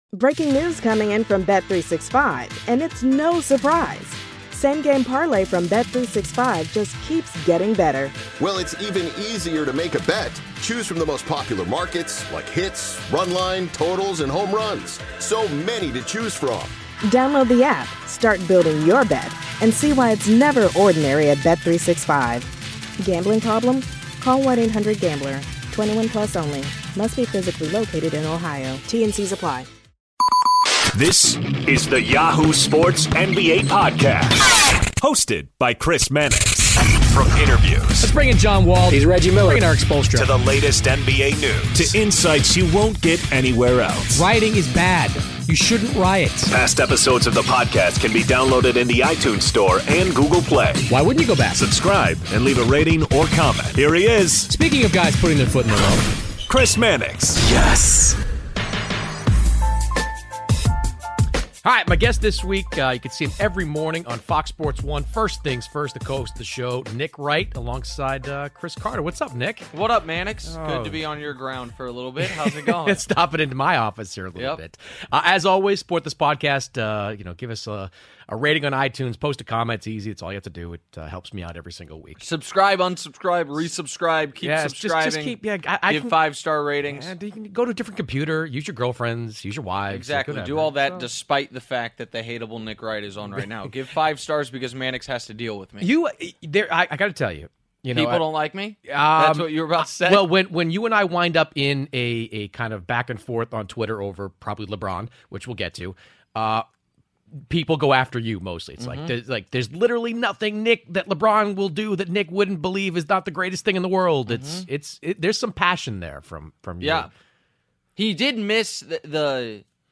Nick Wright of FS1's First Things First The Crossover NBA Show SI NBA Basketball, Sports 4.6 • 641 Ratings 🗓 10 April 2018 ⏱ 58 minutes 🔗 Recording | iTunes | RSS 🧾 Download transcript Summary Joining Chris Mannix of Yahoo Sports this week is Nick Wright, the co-host of First Things First on FS1.